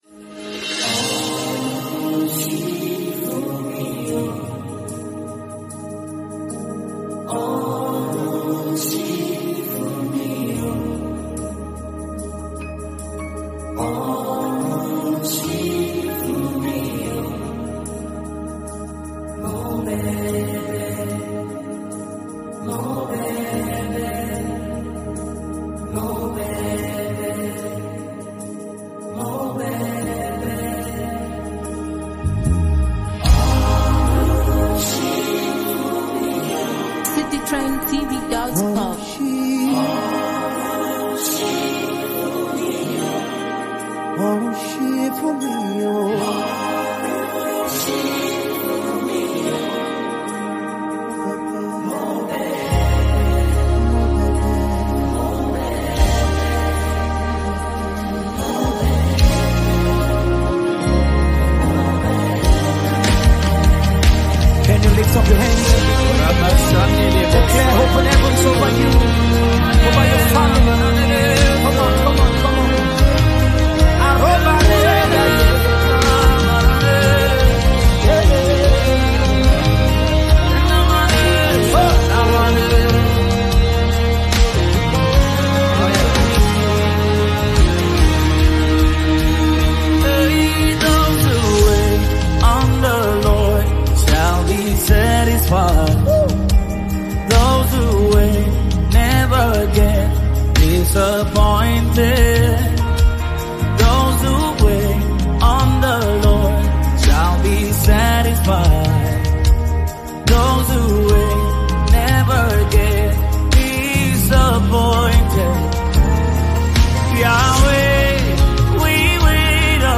worship song
blending heartfelt vocals with deep spiritual expression.